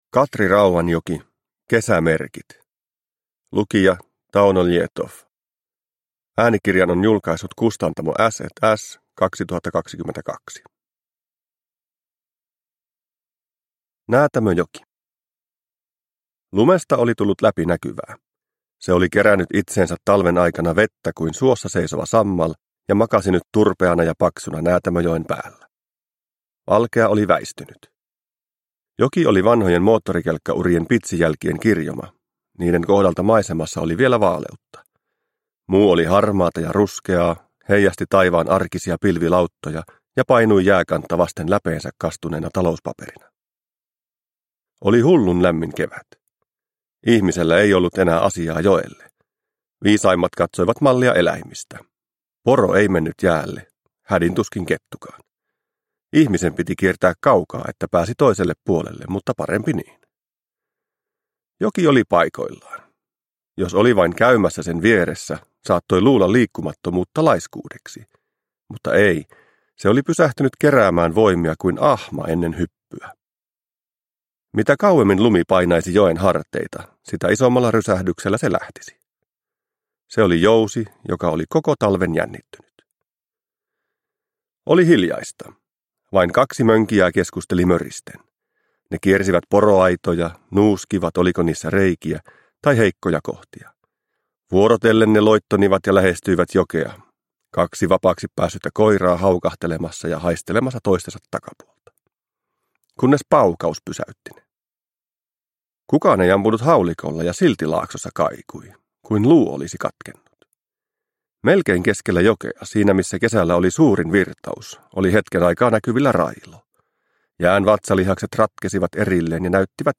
Kesämerkit – Ljudbok – Laddas ner